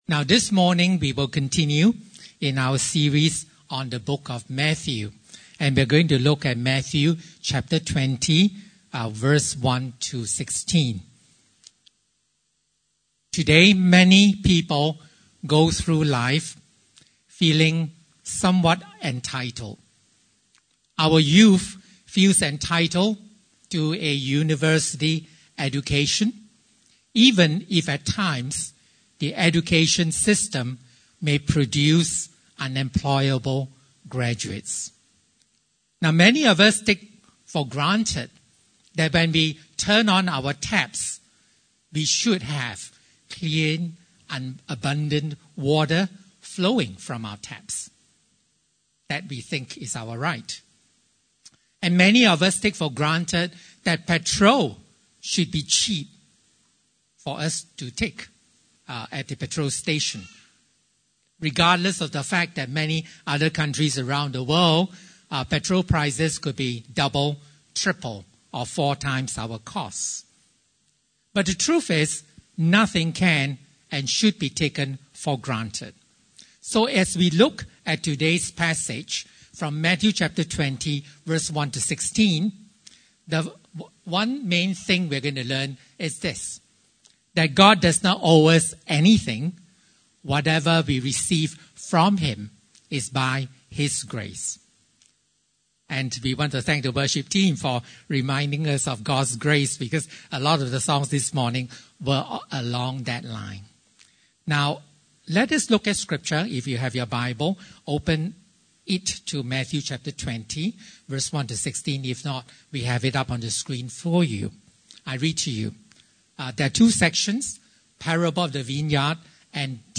Passage: Matthew 20:1-16 Service Type: Sunday Service (Desa ParkCity) « Heavenly Conduct On Earth Jesus